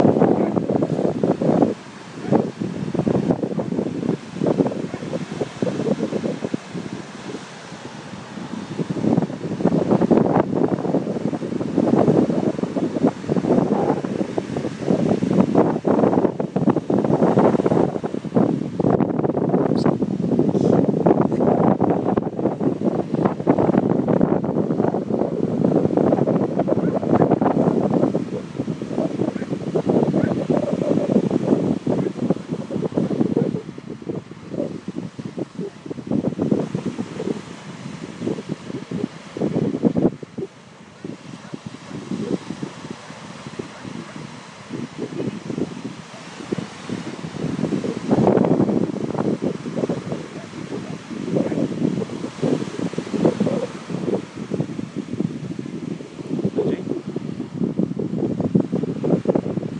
Beach sounds -- the left half